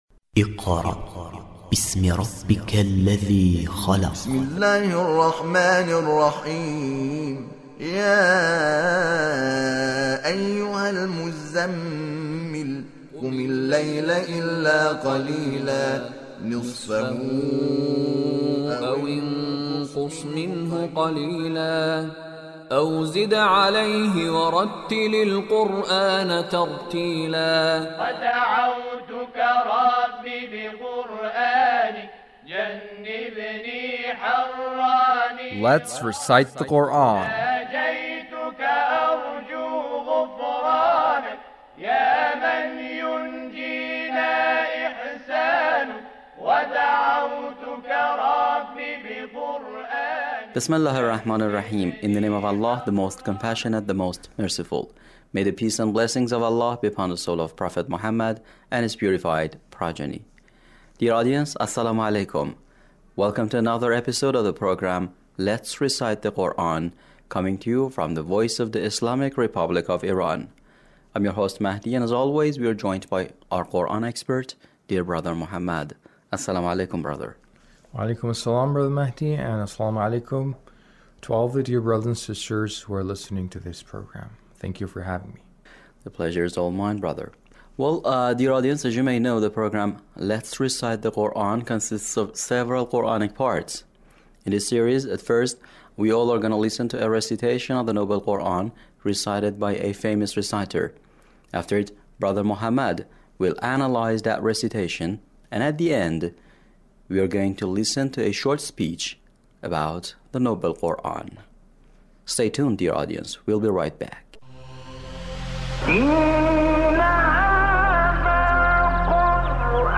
Recitation of sheykh Khalil Alhosari